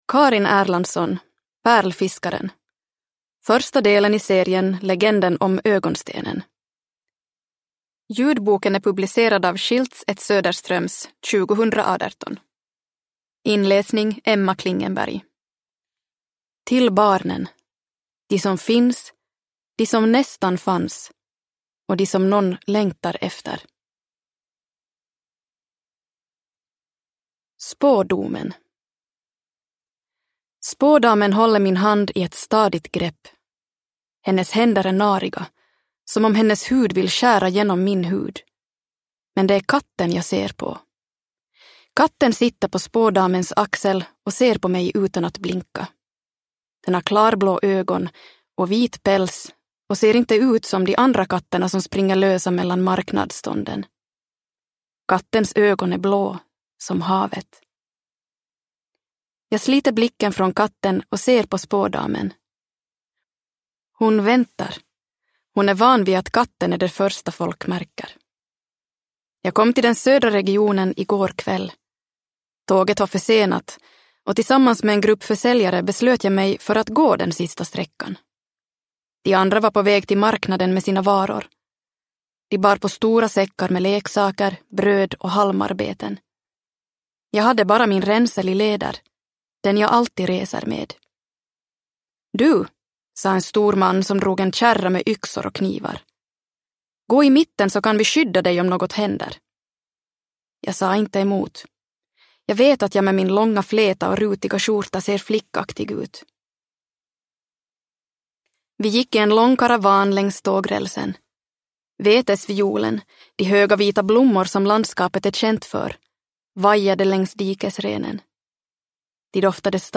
Pärlfiskaren – Ljudbok – Laddas ner